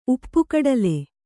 ♪ uppu kaḍale